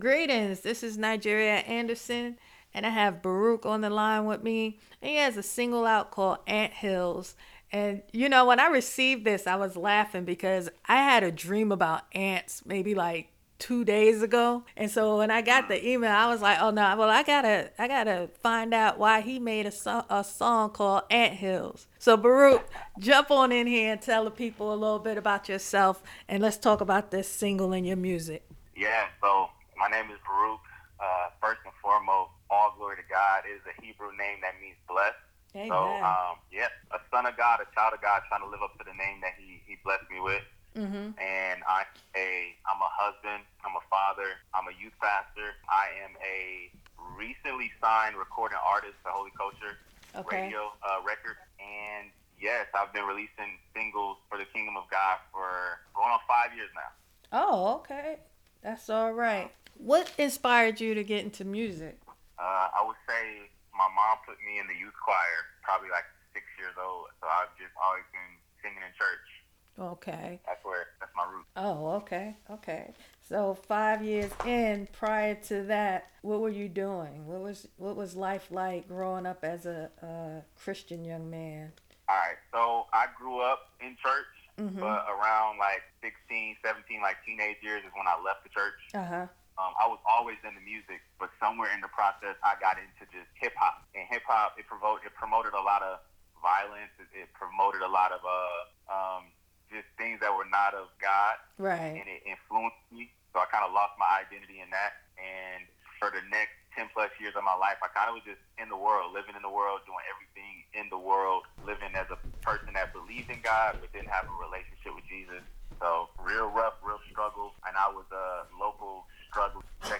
In this interview you are going to hear a very honest report of the goodness of God.